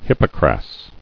[hip·po·cras]